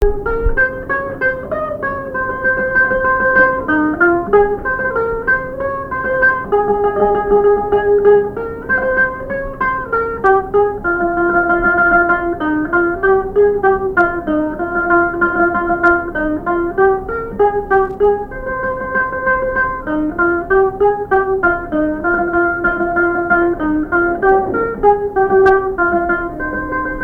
Valse
danse : tango
répertoire au violon et à la mandoline
Pièce musicale inédite